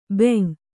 ♪ bem/ben